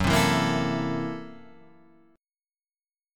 F#7#9b5 chord